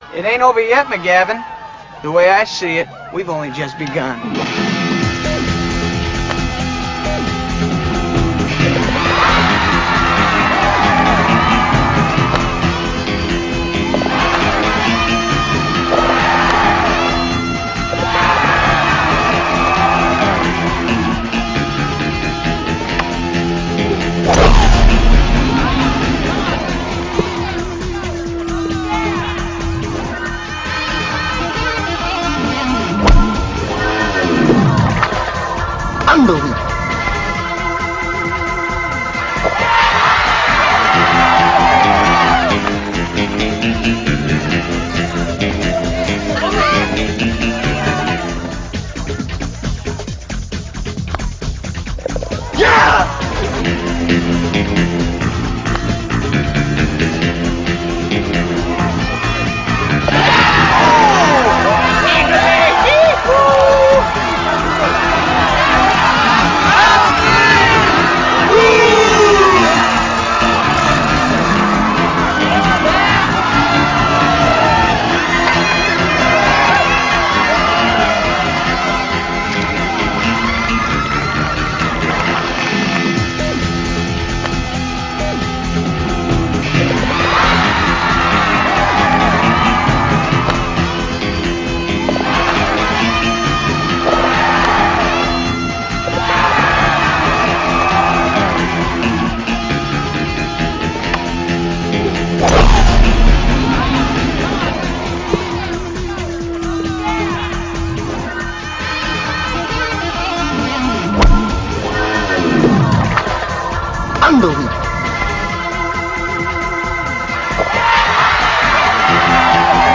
001.mp3 file - I contructed this sound file from a movie clip of Happy Gilmore.